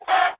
Sound / Minecraft / mob / chickenhurt2